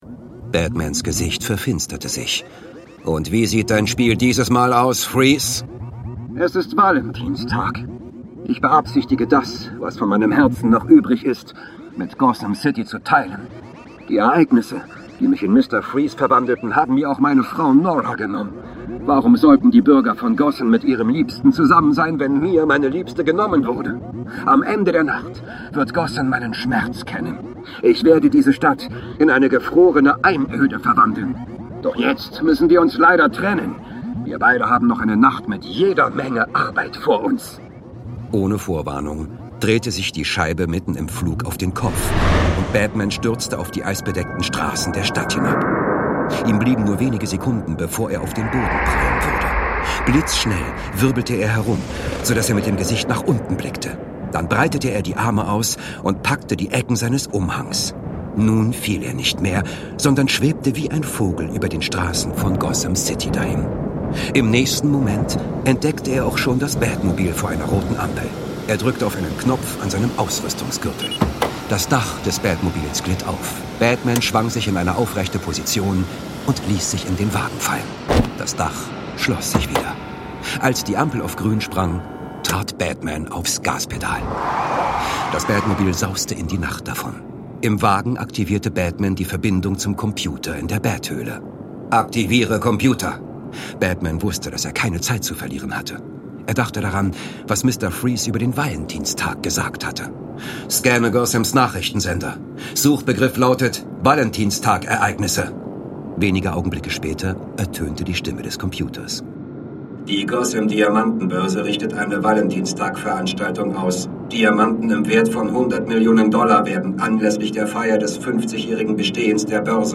Dazu gibt es zahlreiche Geräuscheffekte und eine opulente Musikkulisse!